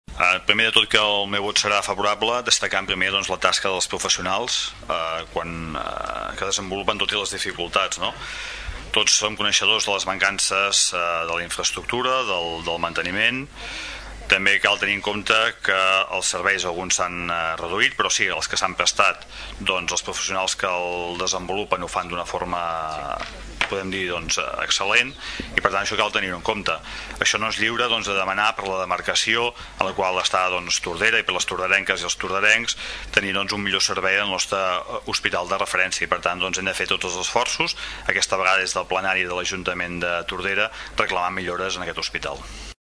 El regidor no-adscrit, Xavier Pla, també hi votava a favor, argumentant que cal fer esforços per reclamar millores.